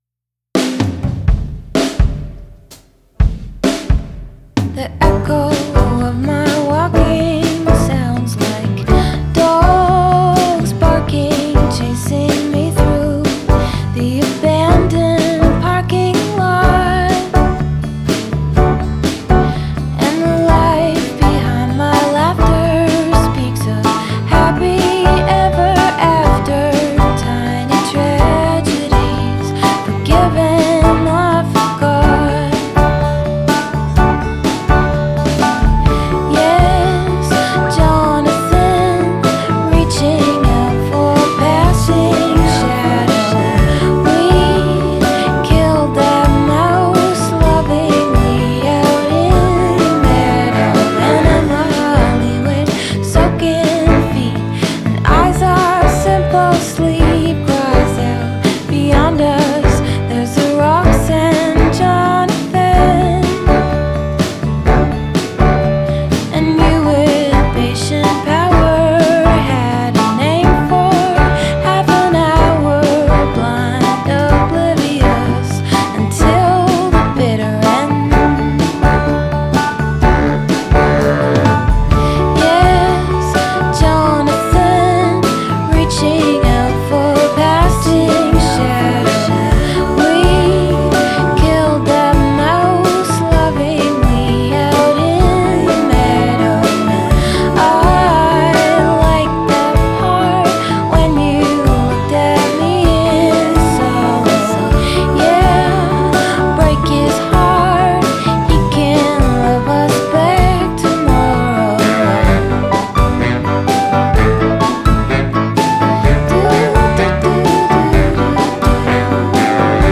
magical, lilting, and steadfast
all forward momentum and friendly, recycling melody.
A singer/songwriter from Guelph, Ontario